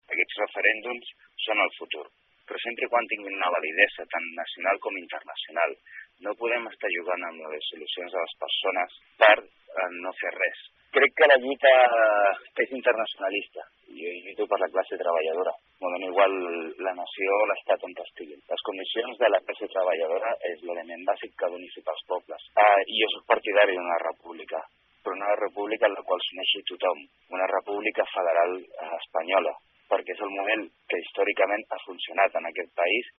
En dues entrevistes sense titulars, tots dos polítics es mantenen en la seva línia i ratifiquen el posicionament de l’Ajuntament.